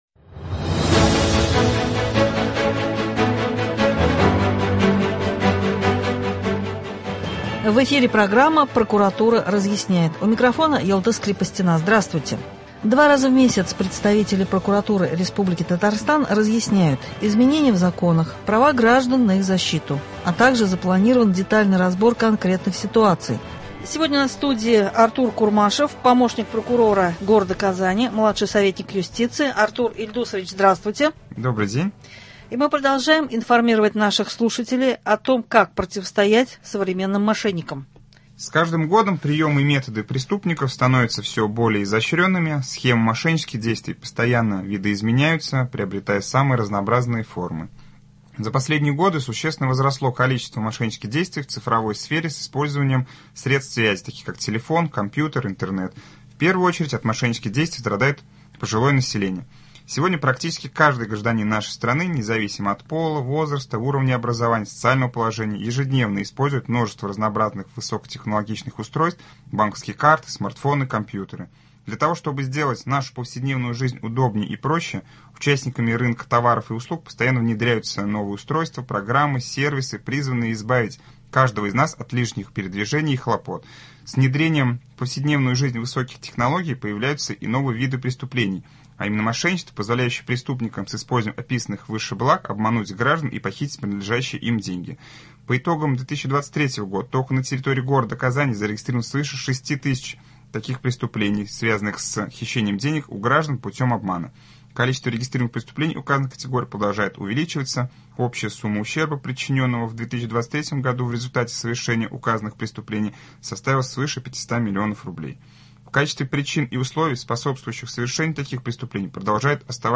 Продолжаем предупреждать о мошеннических схемах, у микрофона помощник прокурора по г.Казани Артур Курмашев.